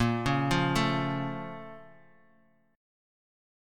A#mM7 chord